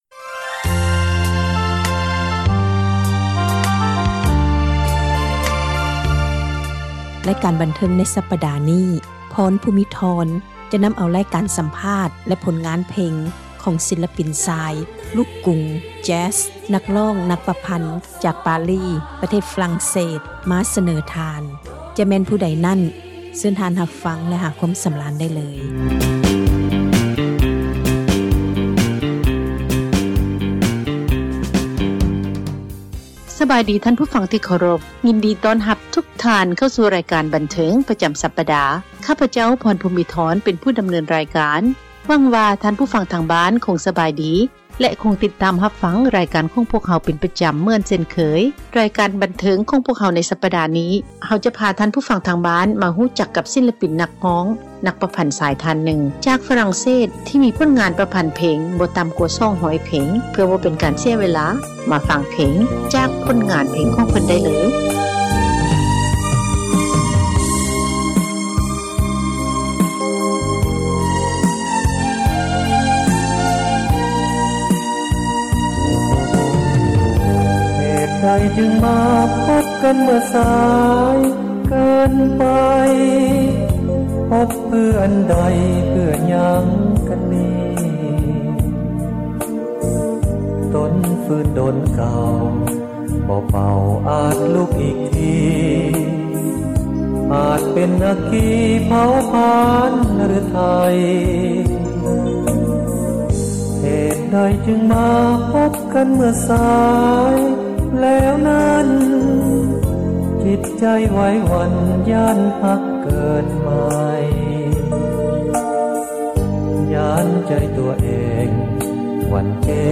ສັມພາດ ສິລປິນນັກຮ້ອງ ແລະ ນັກປະພັນ ປະເພດລູກກຸງ/ແຈ໊ສ ຈາກປະເທດຝຣັ່ງ ທີ່ມີຜົລງານມາຫຼາຍສິບປີ.
ລຳດັບຕໍ່ໄປ ເຊີນທ່ານ ຮັບຟັງ ຣາຍການບັນເທີງ ປະຈໍາສັປດາ